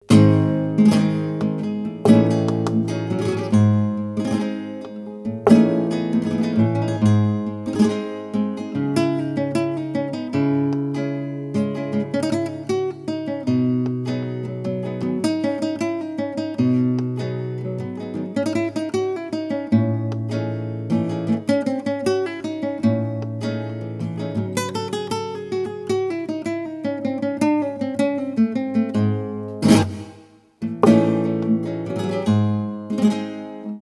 Soleá. Toque de guitarras. Flamenco.
flamenco
guitarra
Sonidos: Música